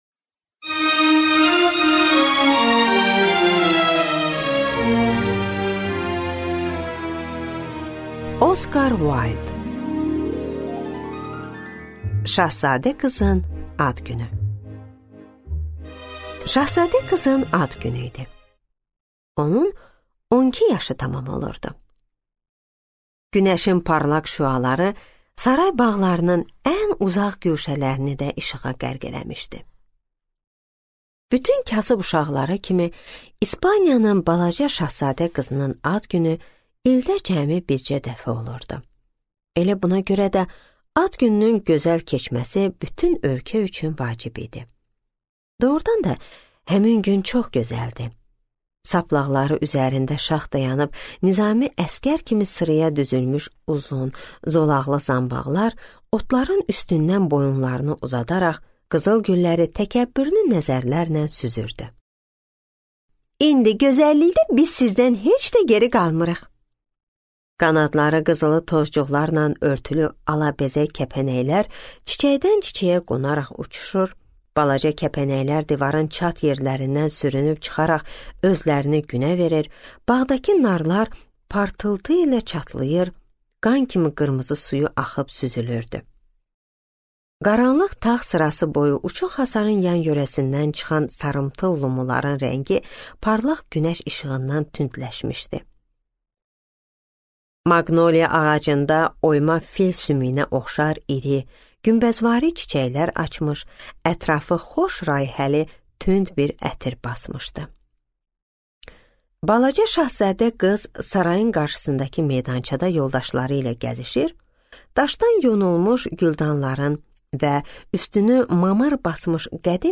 Аудиокнига Şahzadə qızın ad günü | Библиотека аудиокниг
Прослушать и бесплатно скачать фрагмент аудиокниги